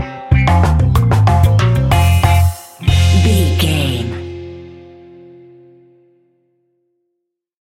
Classic reggae music with that skank bounce reggae feeling.
Aeolian/Minor
dub
laid back
chilled
off beat
drums
skank guitar
hammond organ
percussion
horns